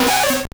Cri de Nidoran♀ dans Pokémon Or et Argent.